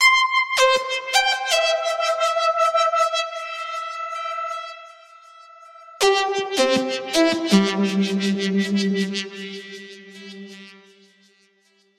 Plops Synth 80 C
描述：调：Cmaj 速度：80bpm 没什么特别的，只是一些简单的血清。
Tag: 80 bpm Chill Out Loops Synth Loops 2.02 MB wav Key : C